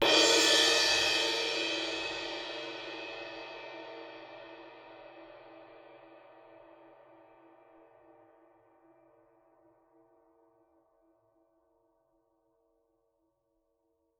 susCymb1-hit_fff_rr2.wav